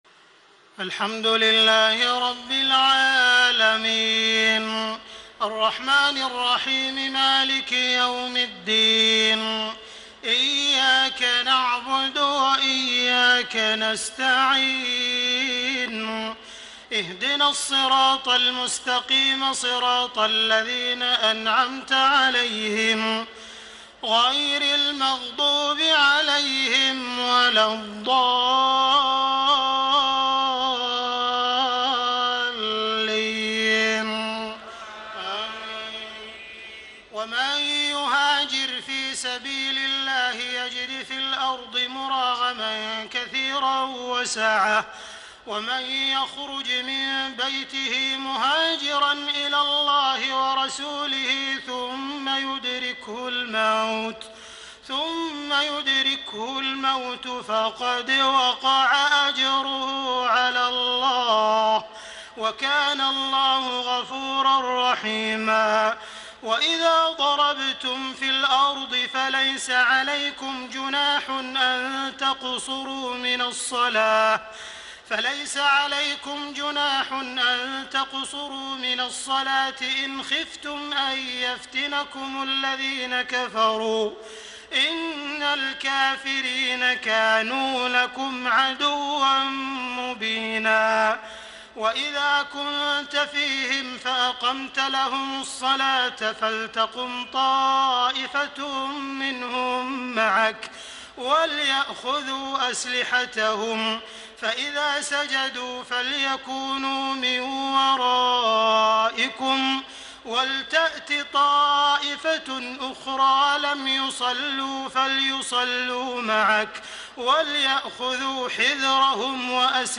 تهجد ليلة 25 رمضان 1434هـ من سورة النساء (100-147) Tahajjud 25 st night Ramadan 1434H from Surah An-Nisaa > تراويح الحرم المكي عام 1434 🕋 > التراويح - تلاوات الحرمين